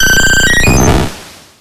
infinitefusion-e18 / Audio / SE / Cries / VAPOREON.ogg